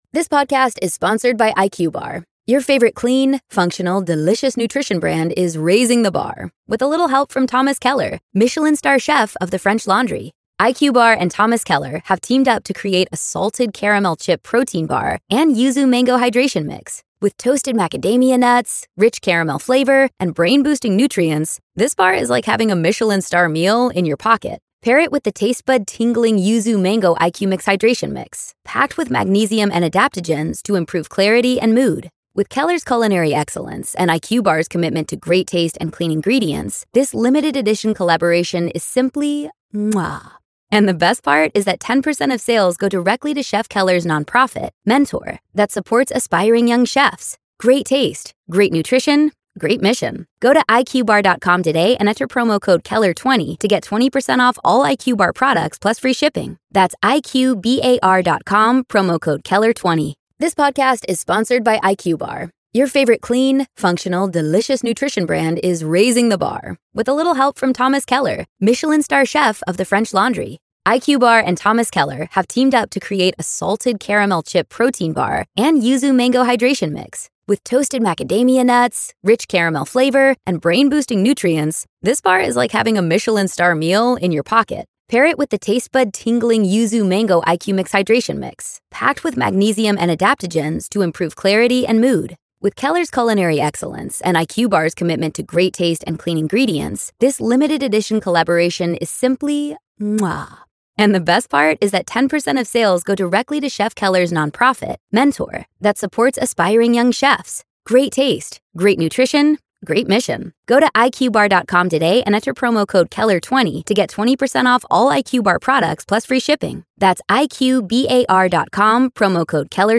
True Crime Today | Daily True Crime News & Interviews / Delphi Murders Judge Rejects Defense’s Cult Killing Theory Ahead of Richard Allen Trial